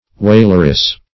Waileress \Wail"er*ess\, n. A woman who wails.